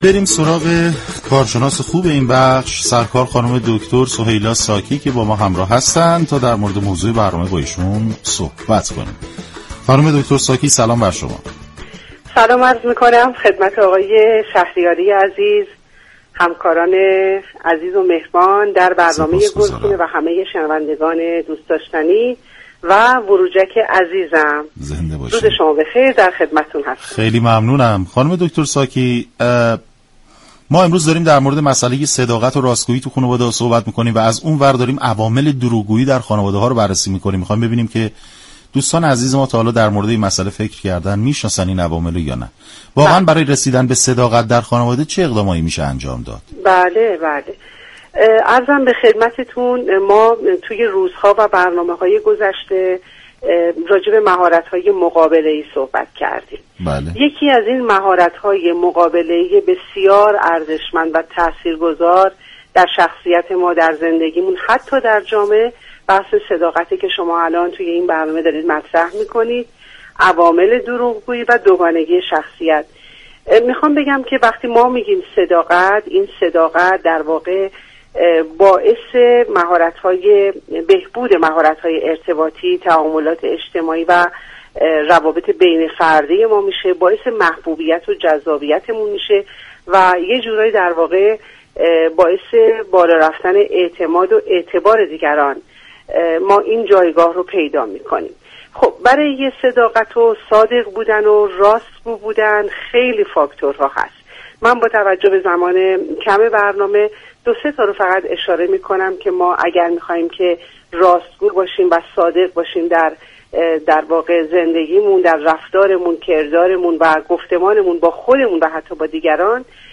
شما می توانید از طریق فایل صوتی پیوست شنونده صحبت های كامل این متخصص روانشناسی باشید.